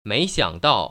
[méi xiăngdào] 메이시앙따오  ▶